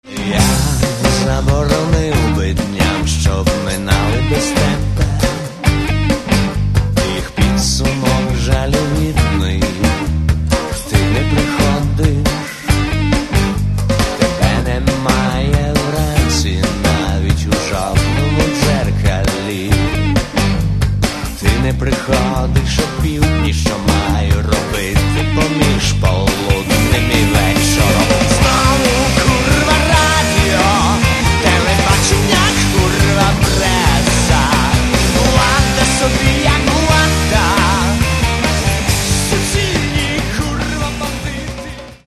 Catalogue -> Rock & Alternative -> Compilations